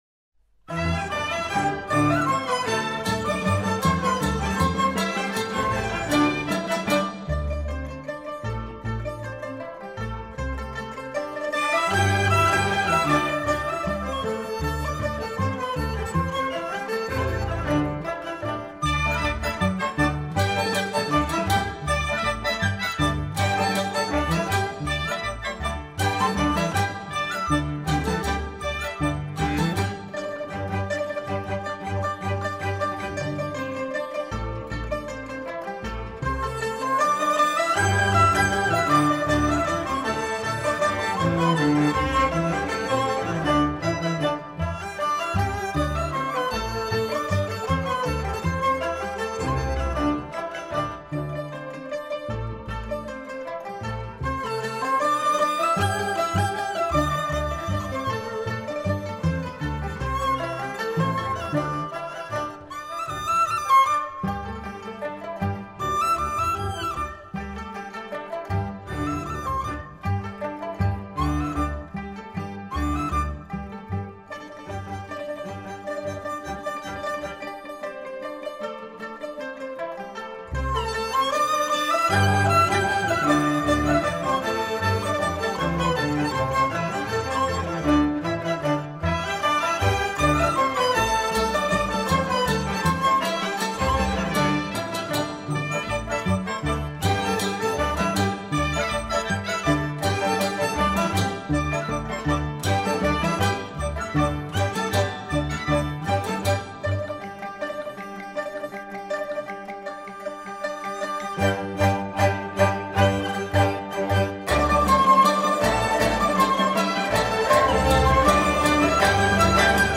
乐曲热烈的快板和欢快的节奏表现了我国民间节庆的欢腾场面。